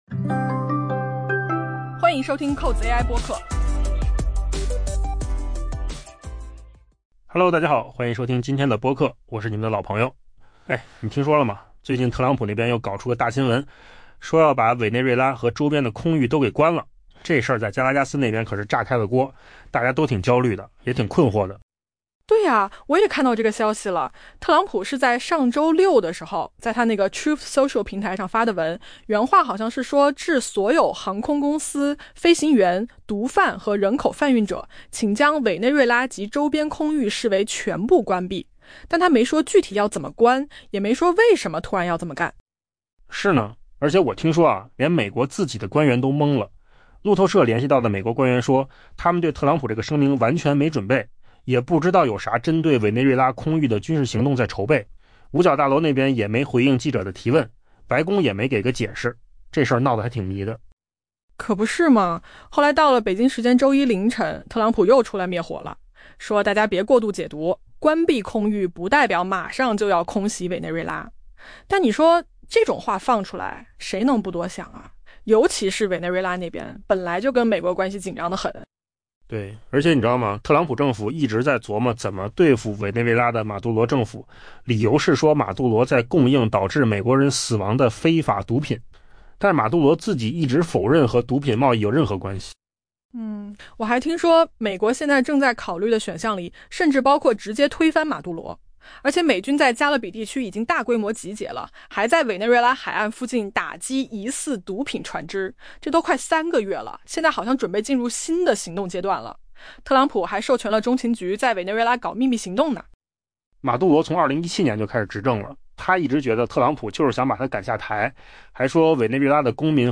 AI 播客：换个方式听新闻 下载 mp3 音频由扣子空间生成 美国总统特朗普上周六表示， 委内瑞拉及周边空域应被 「全部视为关闭」 ，但未提供进一步细节。